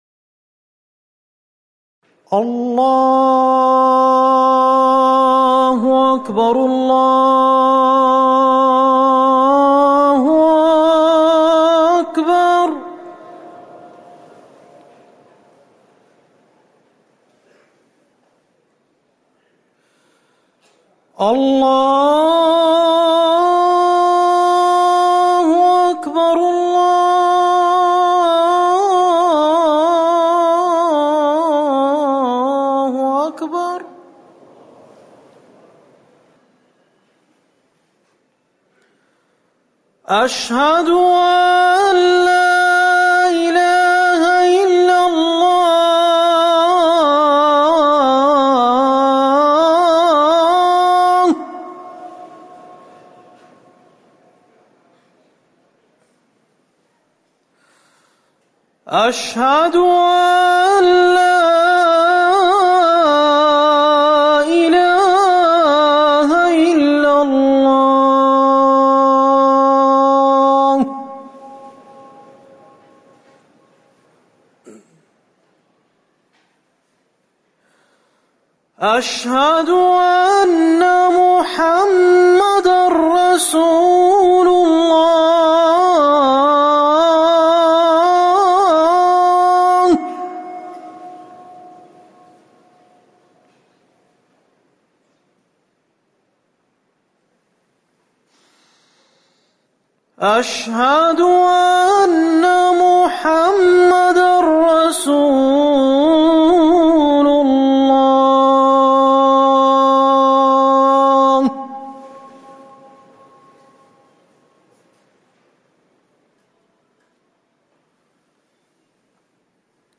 أذان العشاء
المكان: المسجد النبوي